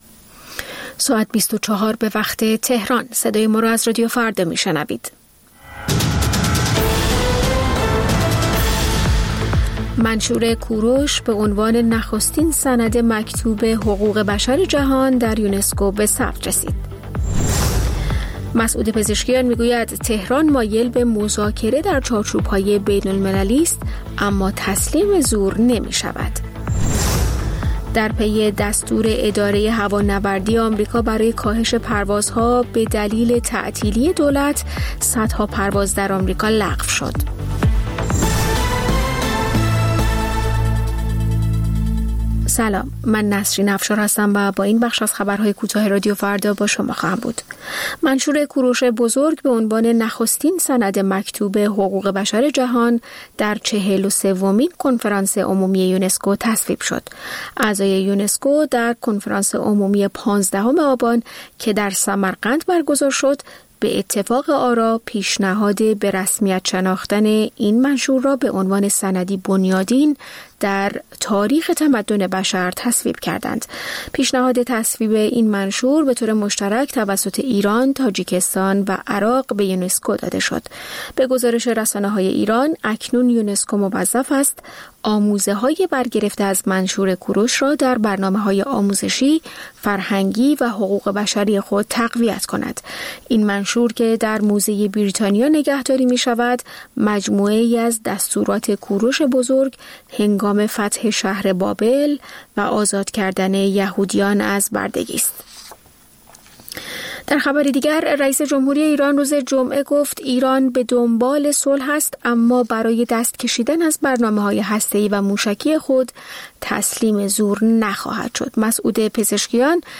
سرخط خبرها ۰۰:۰۰